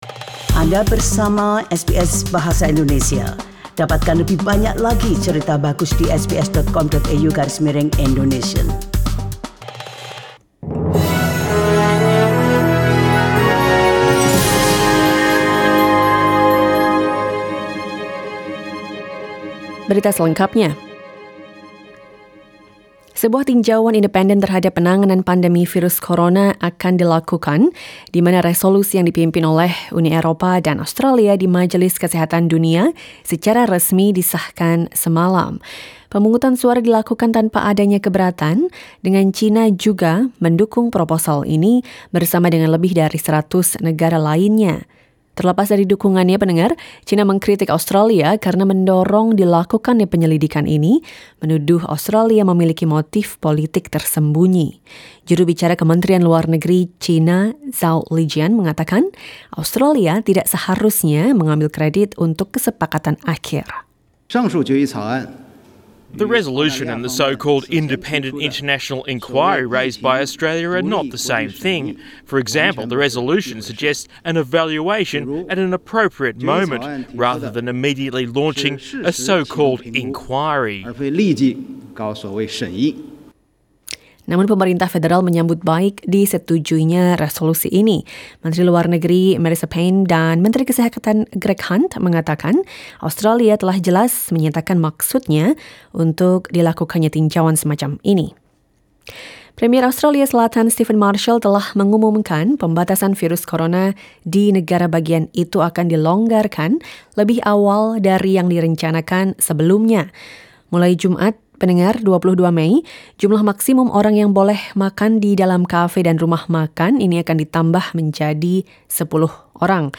SBS Radio news in Indonesian - 20 May 2020